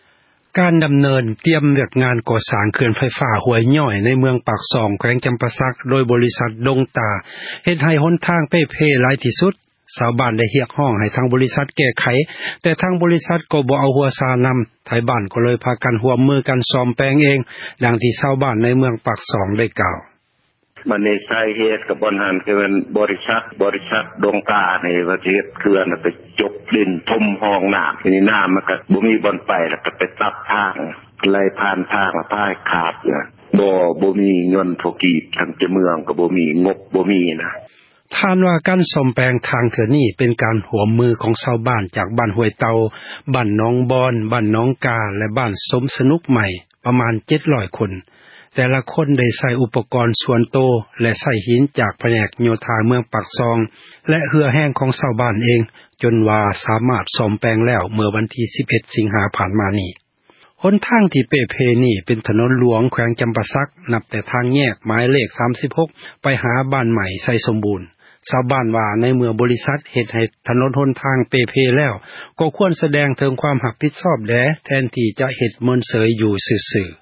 ການດໍາເນີນ ວຽກງານ ກໍ່ສ້າງ ເຂື່ອນ ໄຟຟ້າ ຫ້ວຍຍ້ອຍ ໃນ ເມືອງ ປາກຊ່ອງ ແຂວງ ຈໍາປາສັກ ໂດຍ ບໍຣິສັດ ດົງຕ້າ ເຮັດໃຫ້ ຫົນ ທາງ ເປ່ເພ ຫຼາຍທີ່ສຸດ ຊາວບ້ານ ໄດ້ຮຽກຮ້ອງ ໃຫ້ ທາງ ບໍຣິສັດ ແກ້ໄຂ ສ້ອມແປງ ແຕ່ທາງ ບໍຣິສັດ ກໍບໍ່ສົນ ໃຈນໍາ ຊາວບ້ານ ຈຶ່ງ ຕ້ອງໄດ້ ພາກັນ ສ້ອມແປງ ເອງ. ດັ່ງທີ່ ຊາວບ້ານ ໃນເມືອງ ປາກຊ່ອງ ເວົ້າວ່າ: